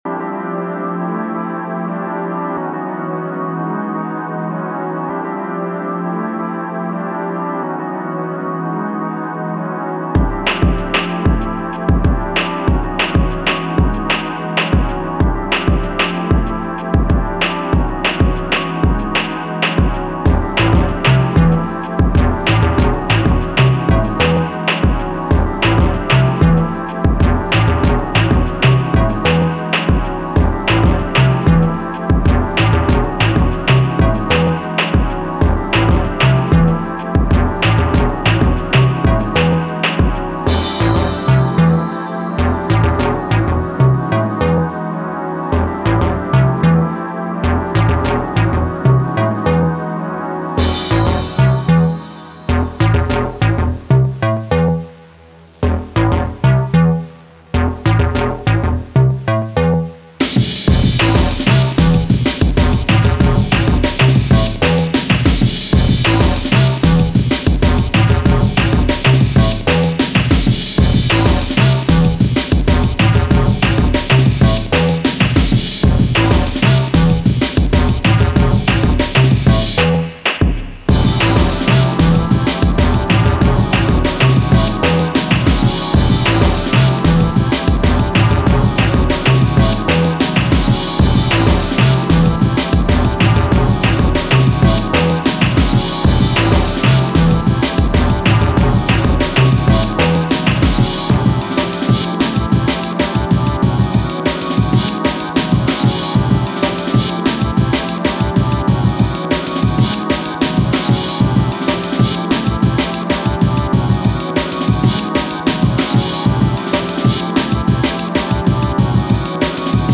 In intro i was like is this bossa nova beat, and then… :boom: